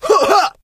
fang_atk_vo_02.ogg